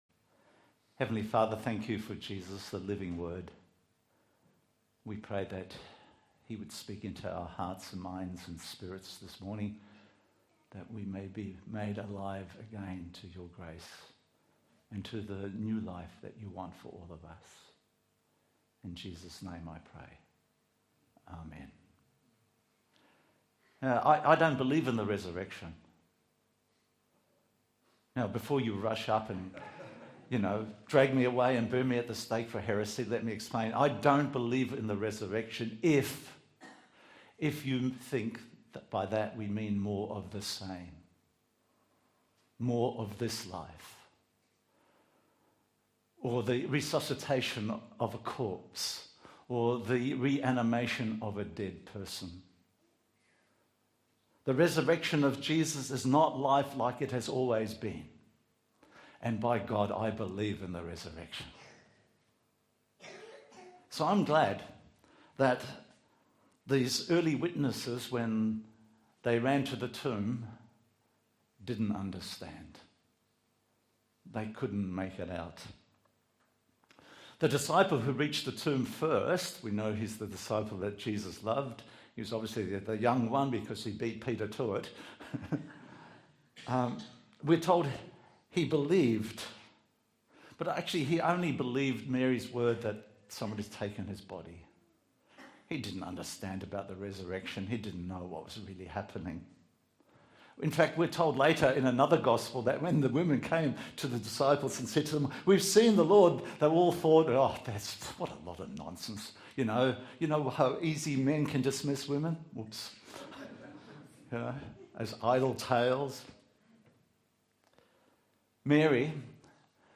Sermons Online Audio Sunday 5 Apr Easter